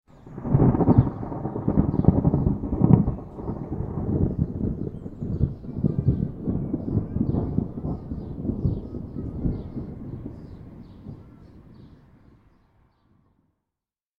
دانلود آهنگ رعدو برق 30 از افکت صوتی طبیعت و محیط
دانلود صدای رعدو برق 30 از ساعد نیوز با لینک مستقیم و کیفیت بالا
جلوه های صوتی